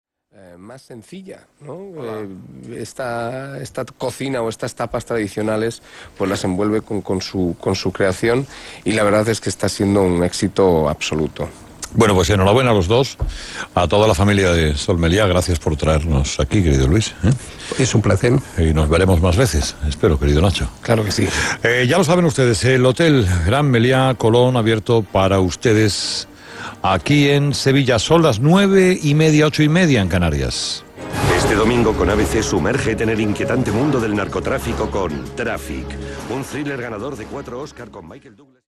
El equipo de “Herrera en la Onda“, matinal que Onda Cero emite diariamente, se aloja en los mejores hoteles de la cadena Sol Meliá, desde donde, cada cierto tiempo, realiza su programa. Desde esas placenteras atalayas lanza Carlos Herrera sus más sentidas apologías sobre la empresa hostelera, cumpliendo con un contrato de promoción que hipoteca la independencia del periodista y de sus colaboradores.
Es Herrera, Carlos, como a él legusta decir, o Don Carlos, como a tantos admiradores les gusta llamarle, uno de esos tipos cuya personalidad arrastra a quienes le rodean, una voz de radio preñada de ingenio, un hombre nacido para ser escuchado.